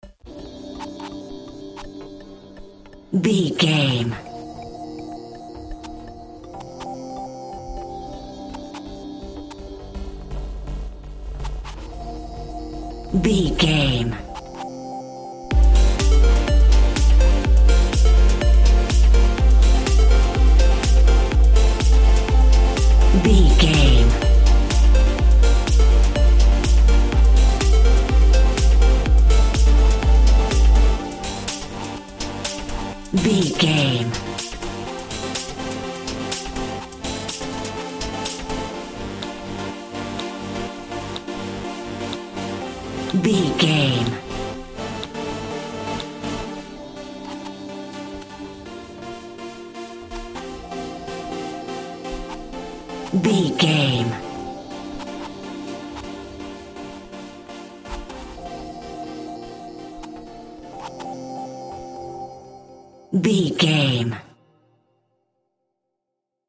Aeolian/Minor
groovy
uplifting
driving
energetic
synthesiser
drum machine
house
techno
synth leads
synth bass
upbeat